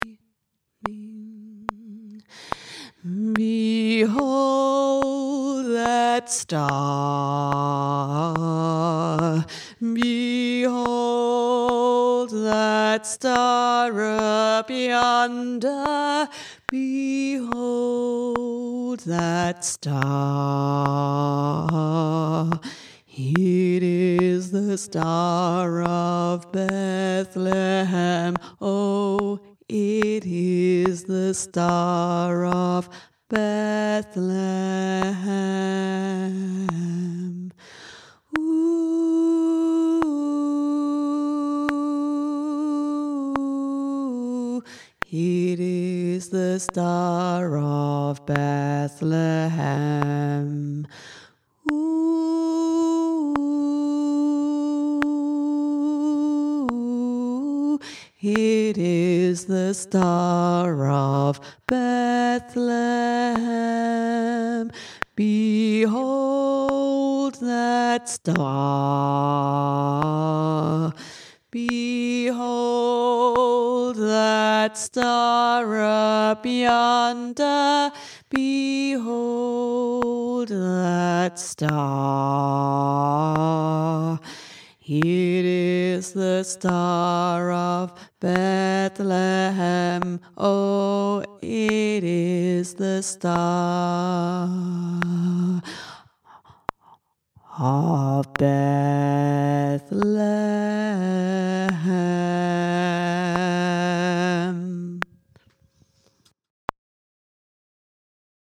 behold-that-star-Tenor.mp3